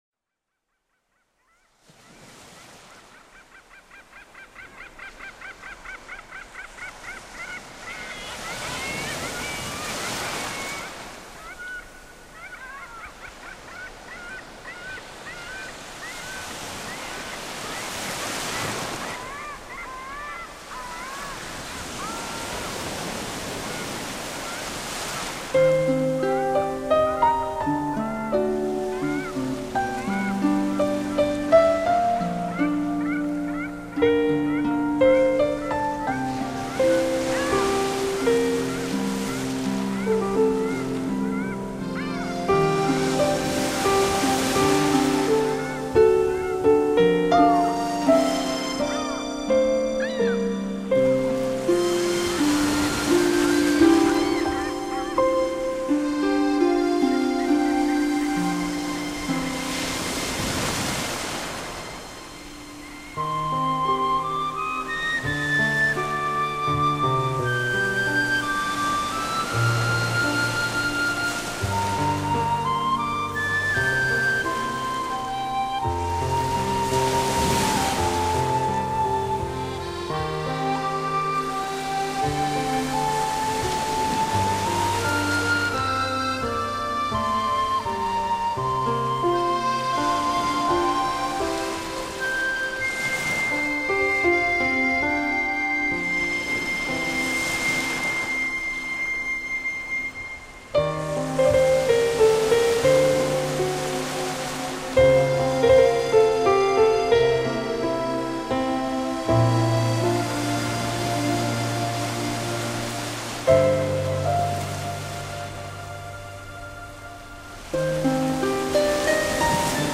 类别：自然音乐
凯尔特音乐和自然声音的结合比较完美